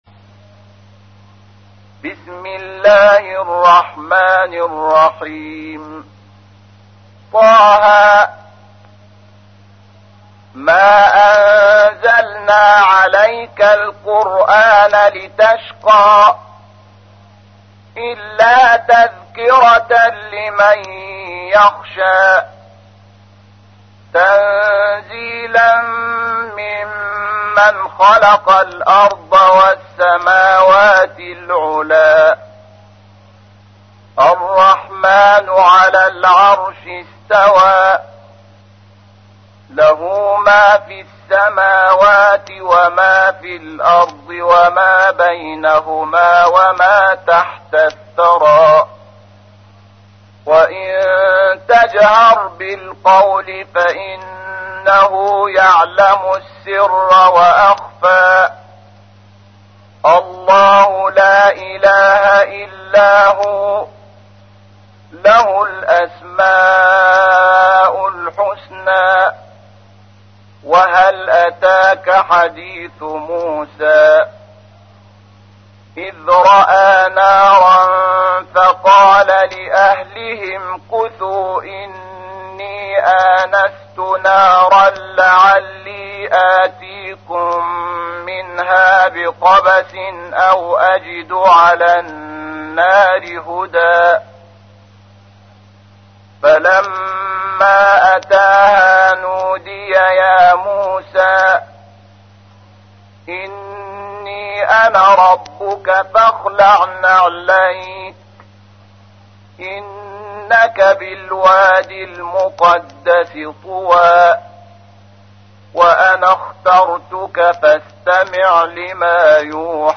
تحميل : 20. سورة طه / القارئ شحات محمد انور / القرآن الكريم / موقع يا حسين